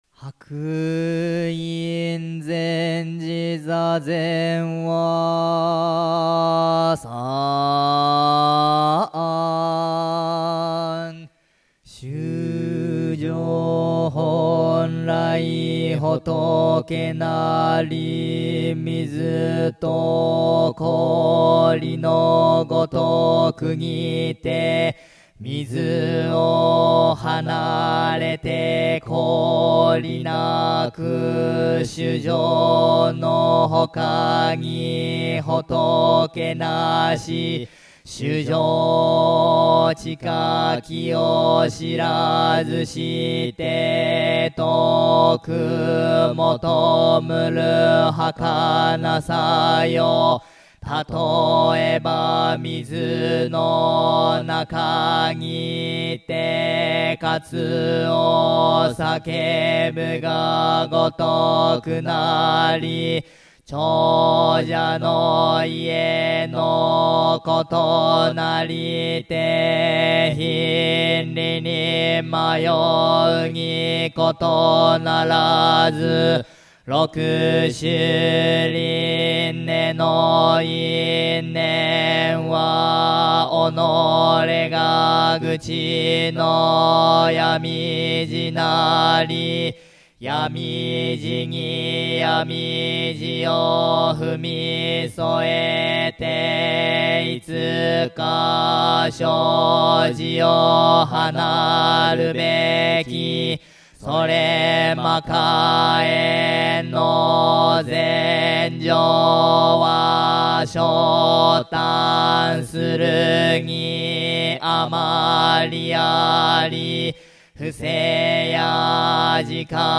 お経を聴く | 南紀白浜・聖福寺（しょうふくじ）公式サイト